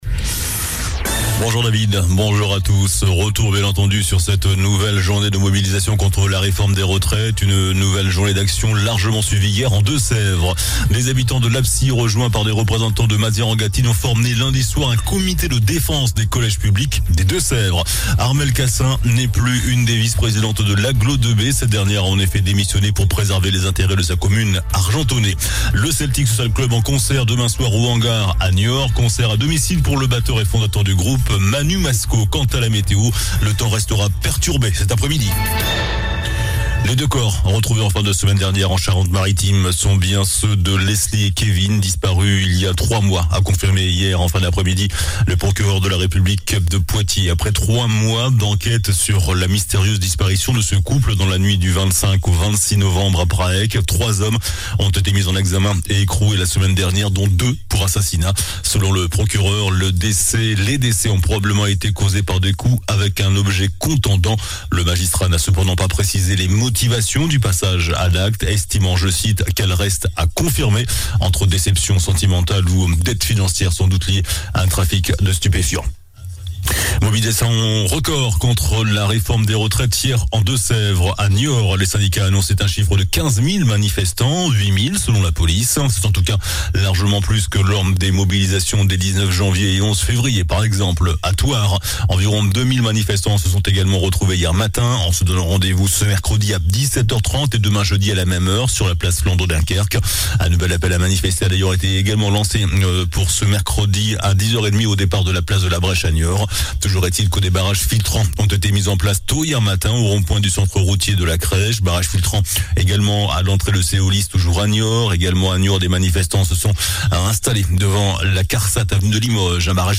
JOURNAL DU MERCREDI 08 MARS ( MIDI )